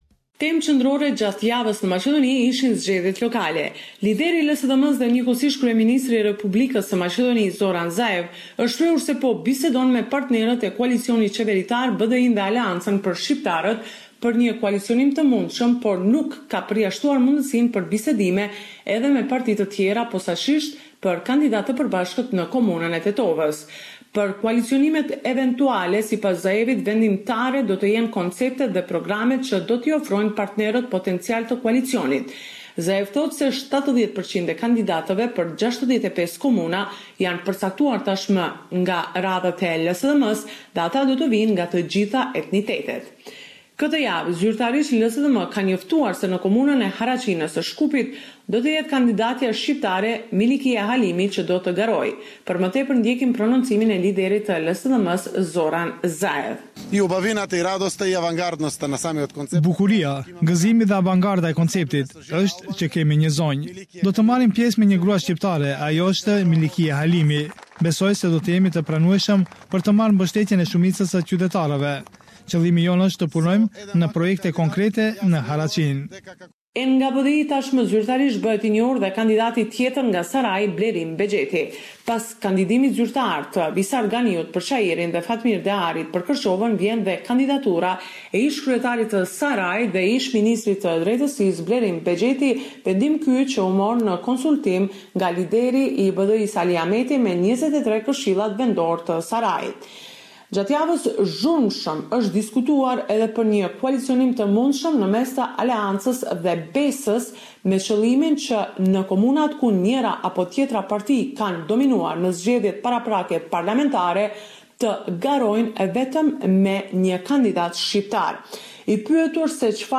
Weekly report.